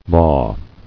[maw]